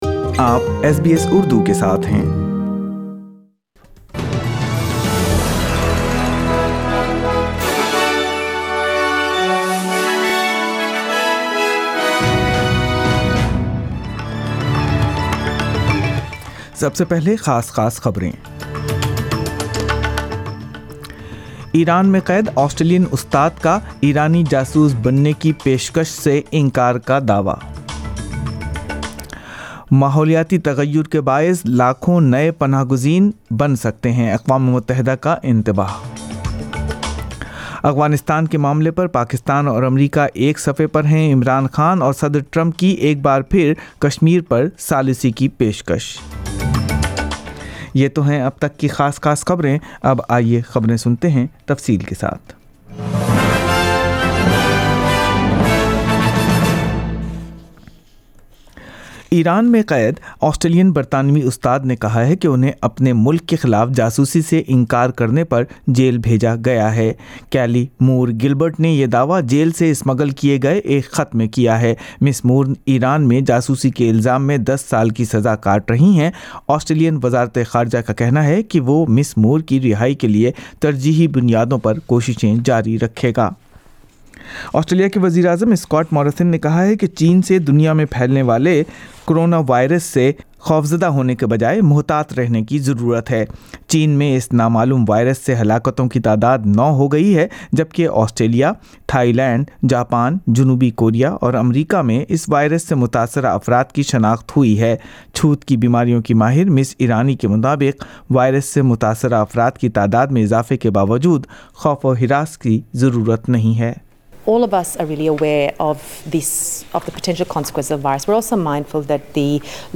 Urdu News 23rd. Jan 2020